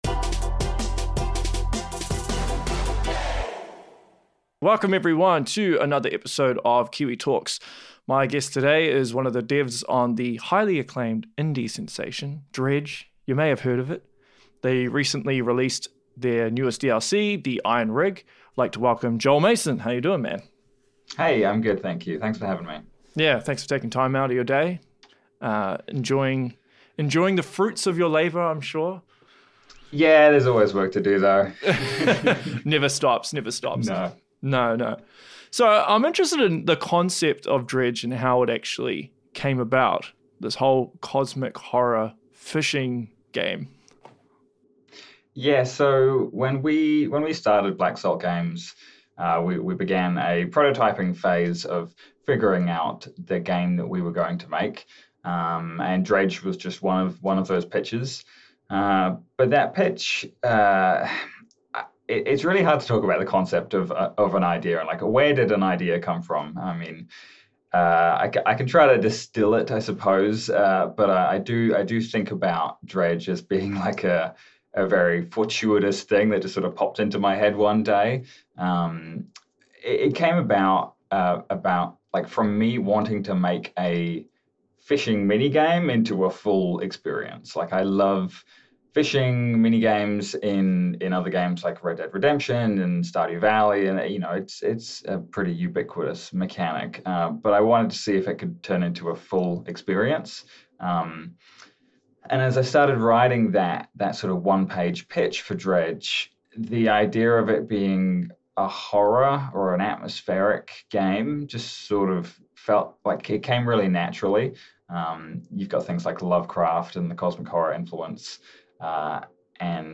Dredge Developer Interview